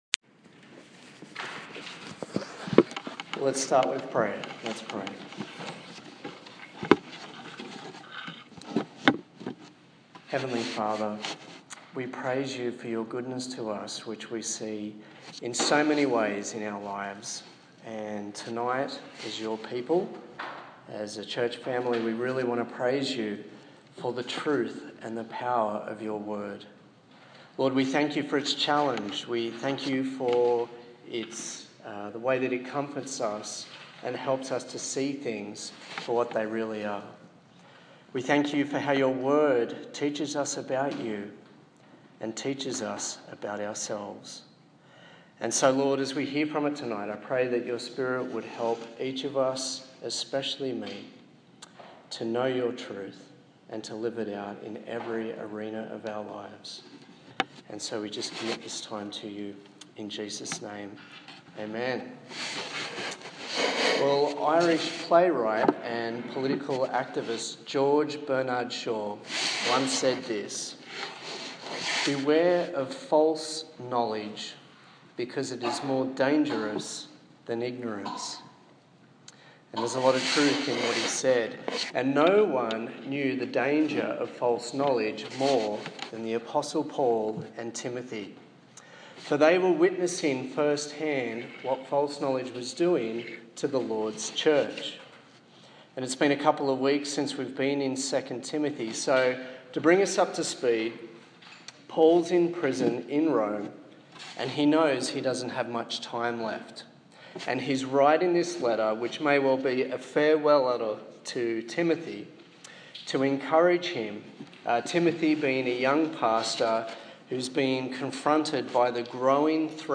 Malignant Heresy Preacher